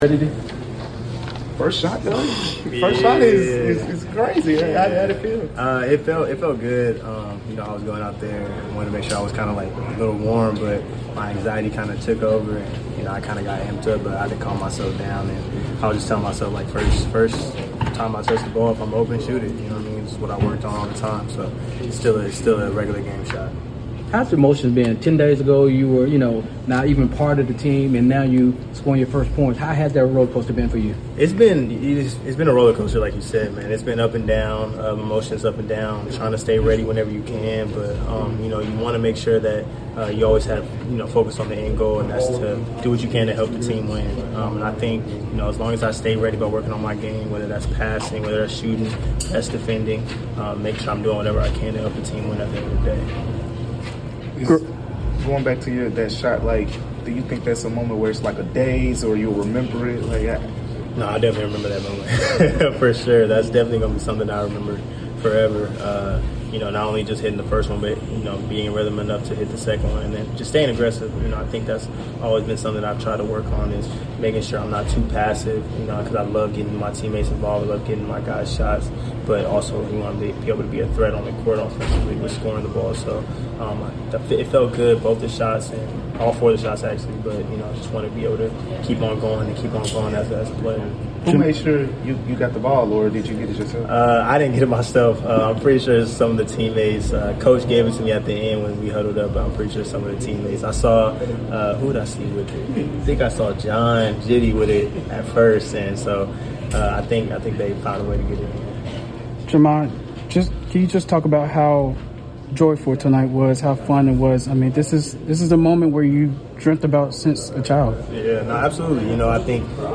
Memphis Grizzlies Guard Jahmai Mashack Postgame Interview after defeating the Sacramento Kings at FedExForum.